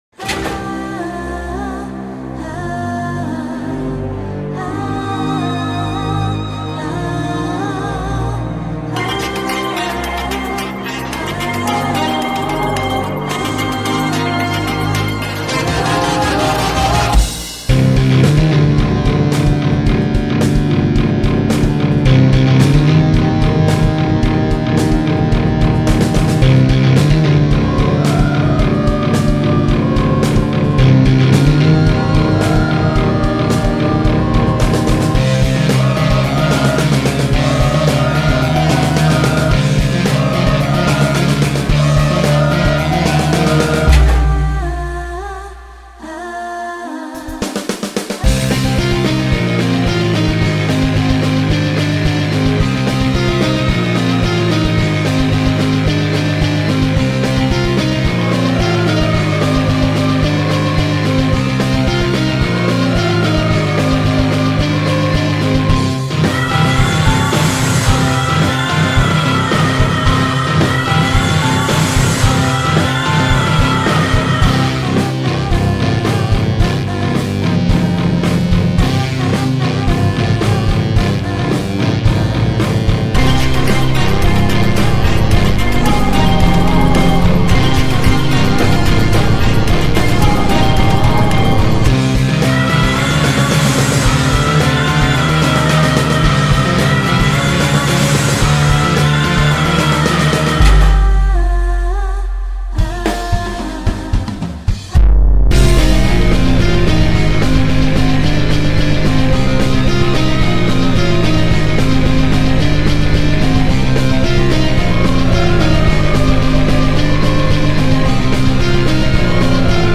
I finally figured out how to fix the audio!
The Dazzlings were awesome and deserve an awesome battle theme!